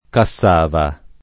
[ ka SS# va ]